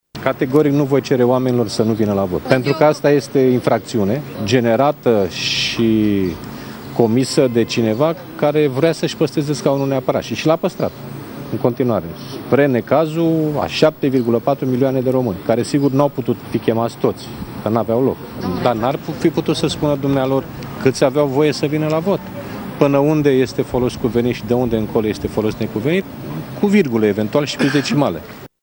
Iată ce a declarant președintele PSD, Liviu Dragnea, după 6 ore de proces: